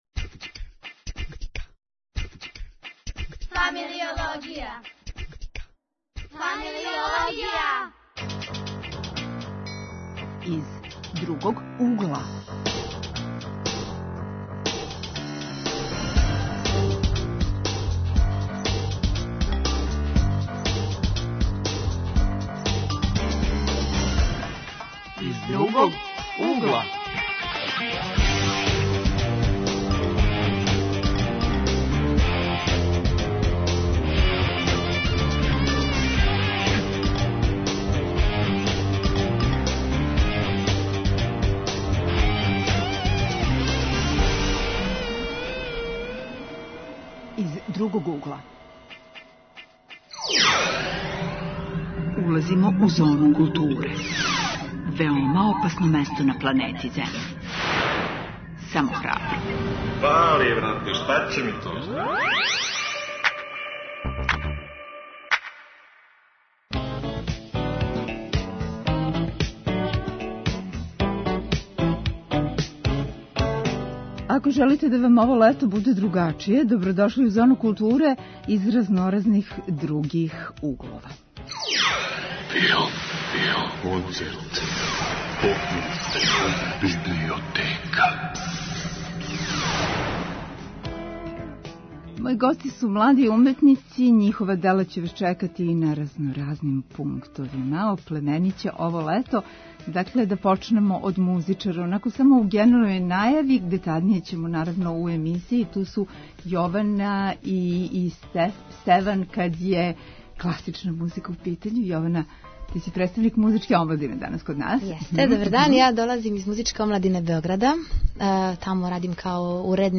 Гости: млади уметници: музичари, сликари, песници...